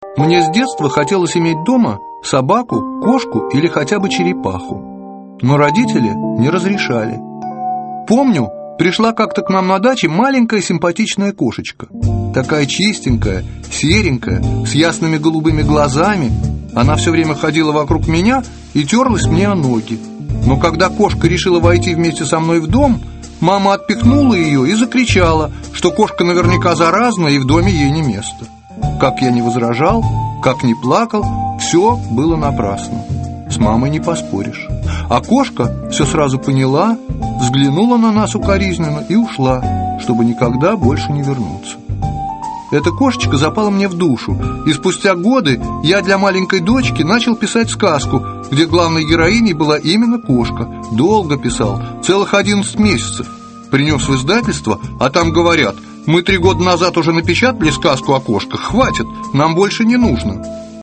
Аудиокнига Сдобная Лиза | Библиотека аудиокниг